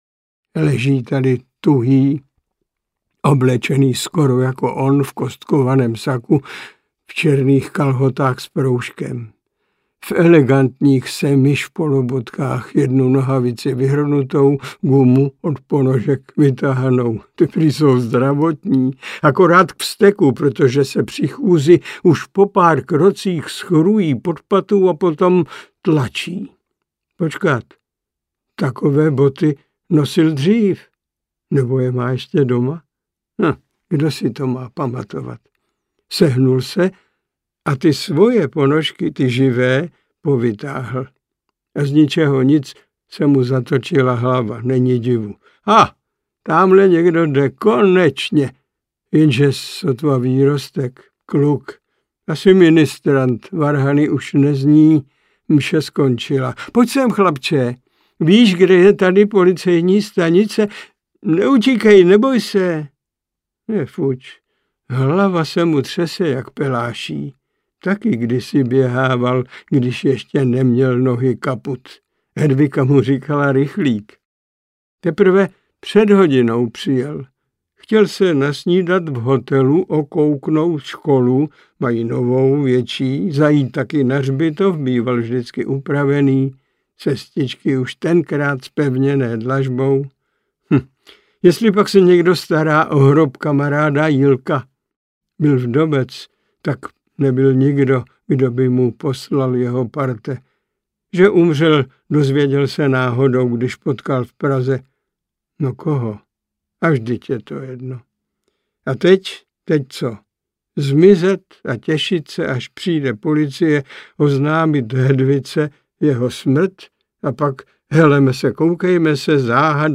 Nebožtík na rynku audiokniha
Ukázka z knihy